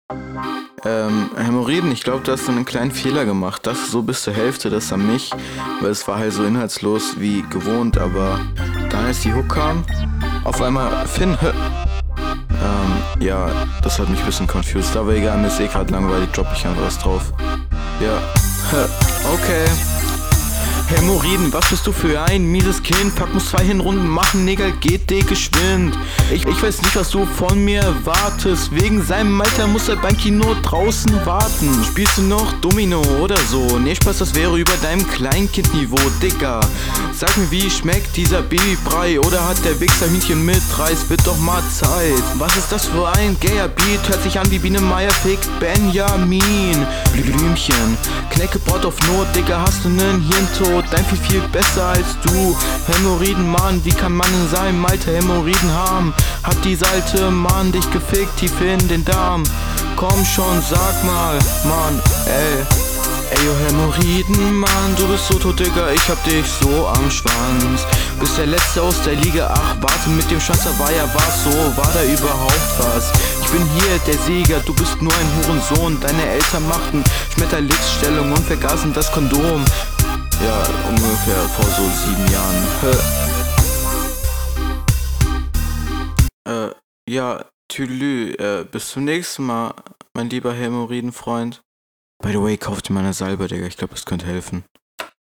Stimmlich ist das zwar besser, aber der Flow ist nicht wirklich stark.
Deine Soundqualität ist nicht unbedingt schlecht, jedoch ist die Stimme etwas drucklos.